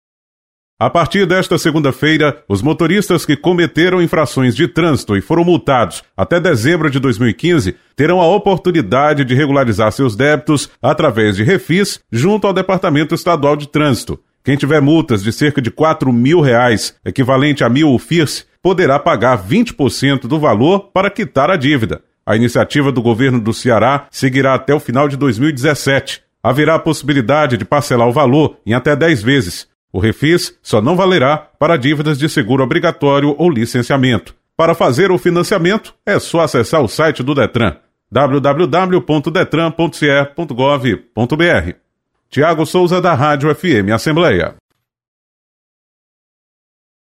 Motoristas podem refinanciar multas em atraso. Repórter